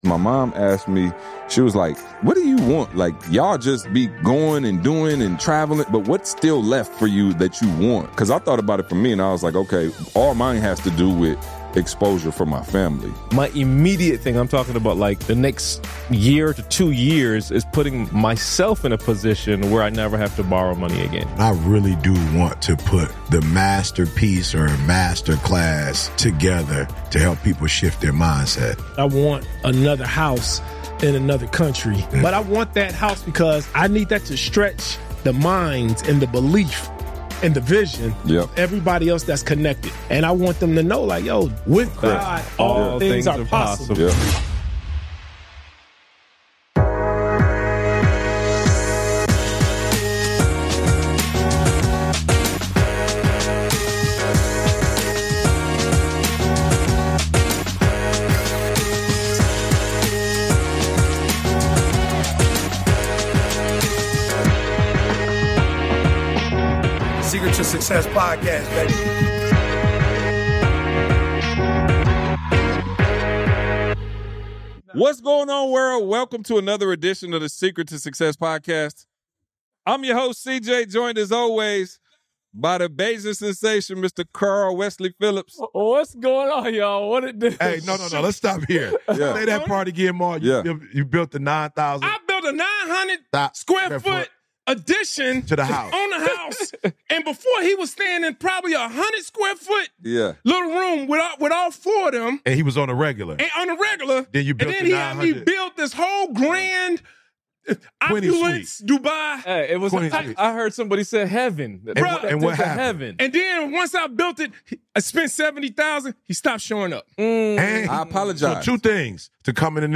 In this raw conversation